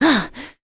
Pavel-Vox_Attack2.wav